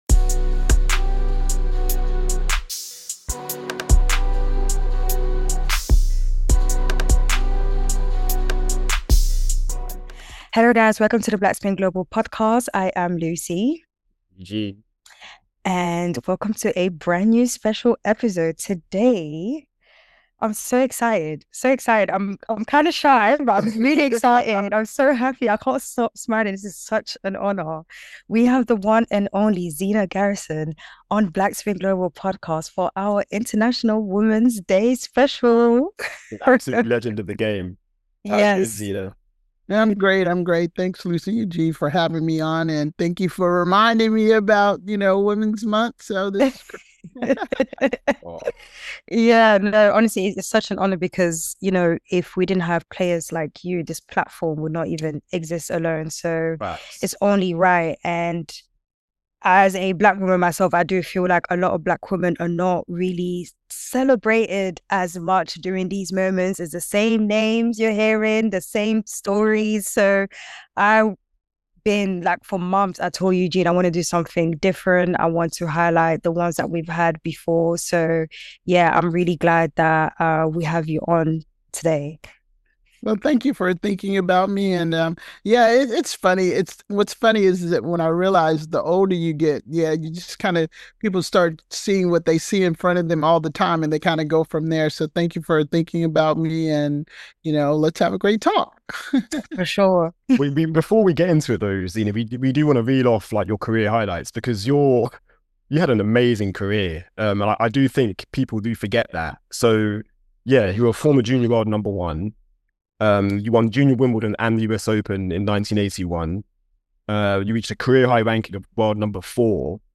Zina Garrison Interview